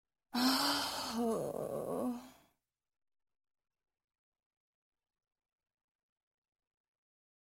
Звуки тяжелого вздоха
Звук женского вздоха